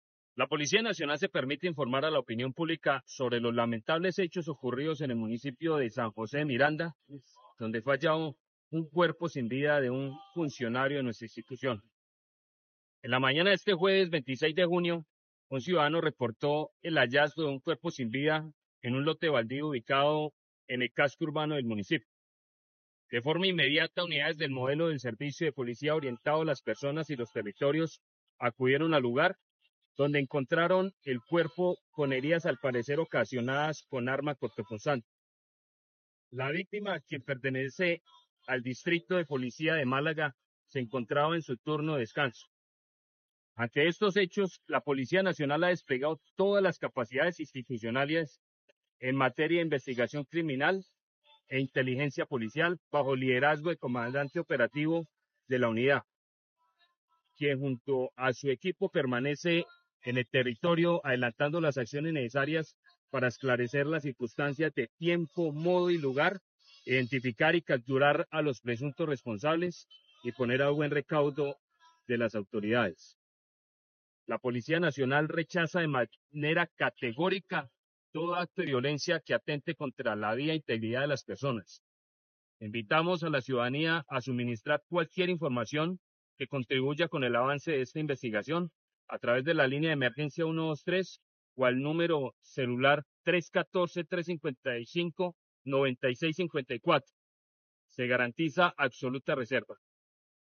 Coronel Gustavo Henao, Comandante Departamento de Policía Santander (E).